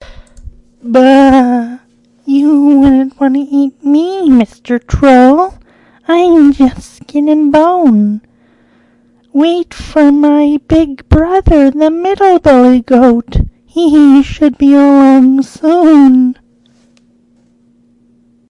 叙述者2
声道立体声